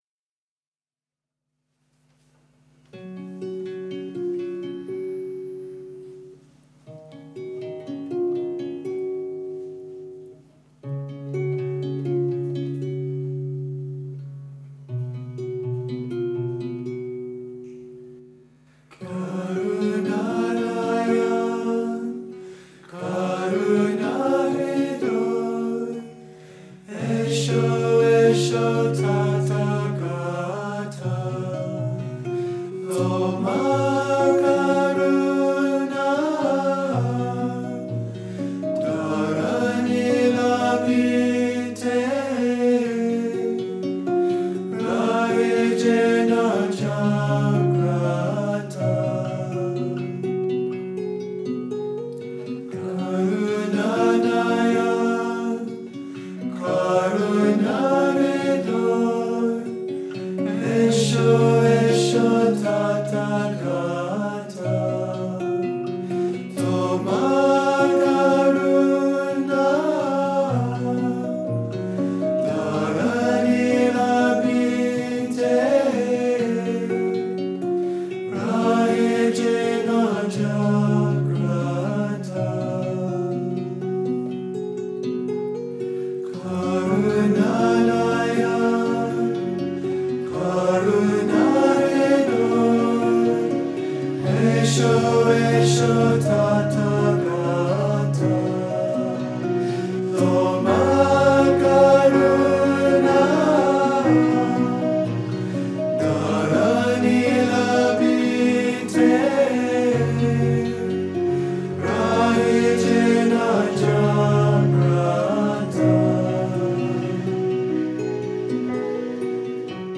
A live recording
a group of male musicians from the UK.